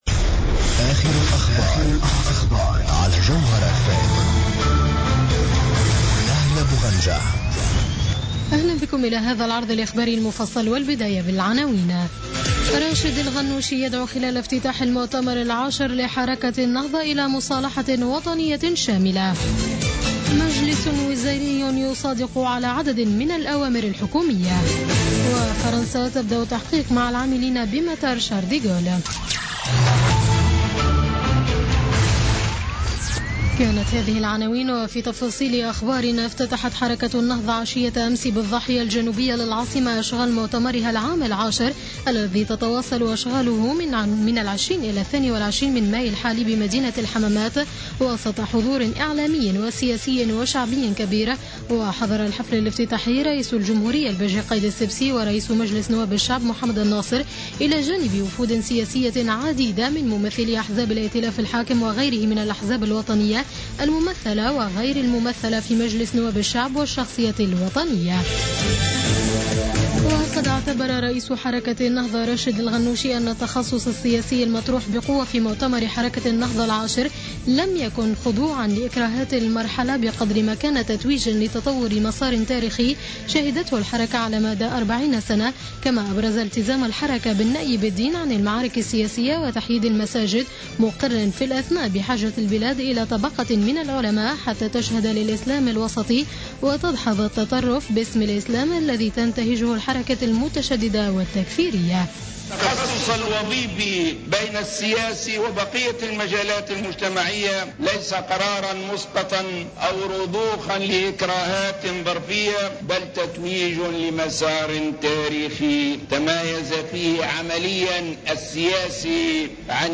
نشرة أخبار منتصف الليل ليوم السبت 21 ماي 2016